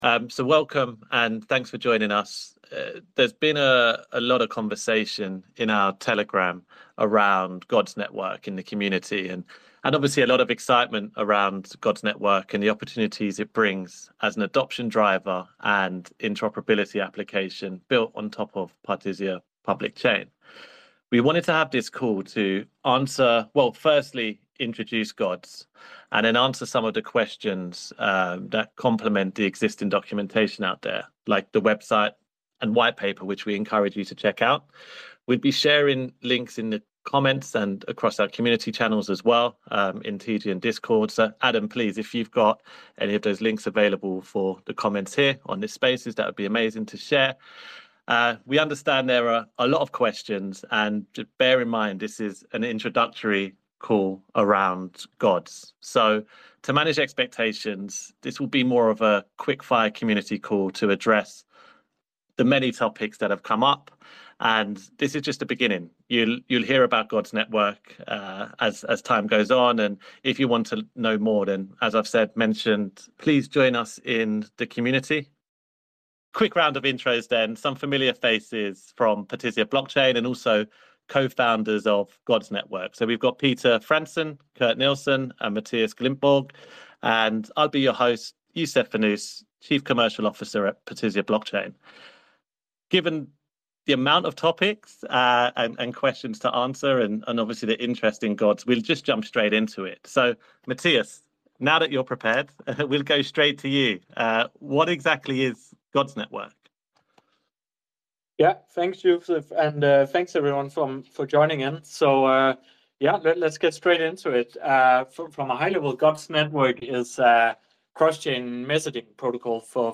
Last week, we held a GODS Network Community Call, where we explored the new transformative, subscription-based, cross-chain messaging protocol being built on Partisia Blockchain. It was a lively session with great questions from the community, and some interesting insights from the team.